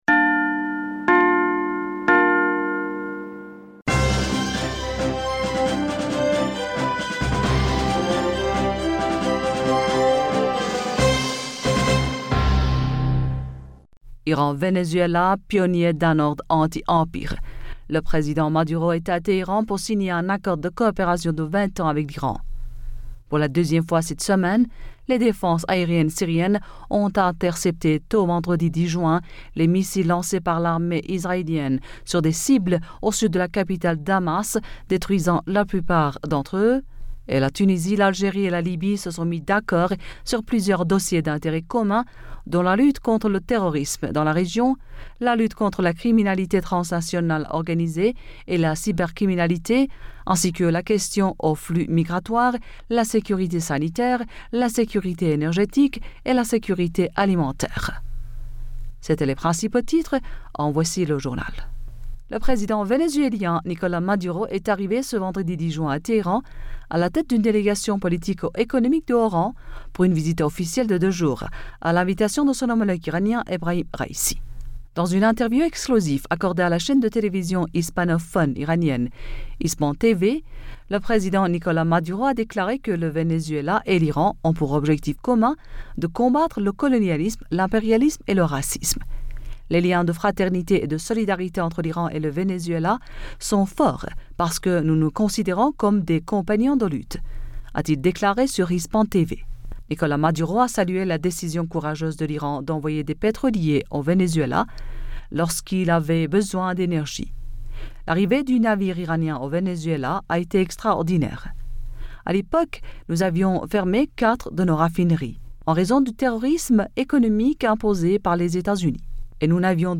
Bulletin d'information Du 11 Juin